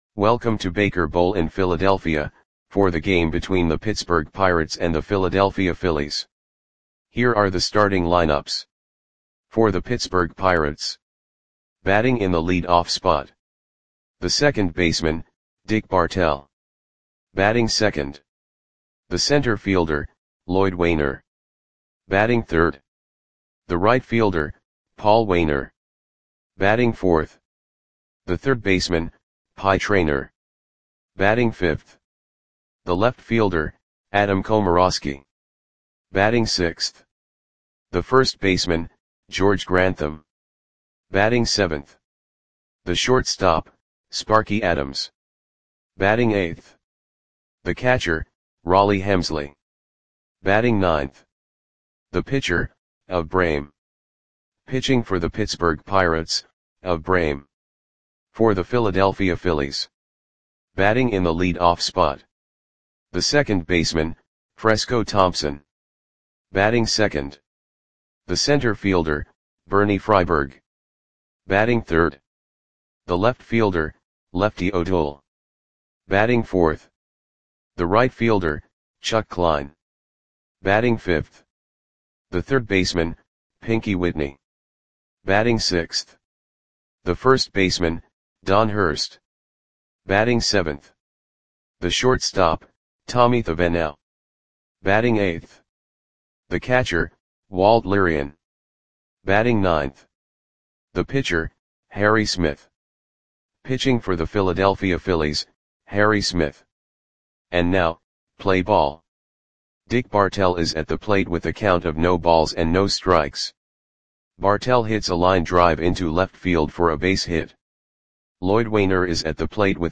Audio Play-by-Play for Philadelphia Phillies on August 27, 1929
Click the button below to listen to the audio play-by-play.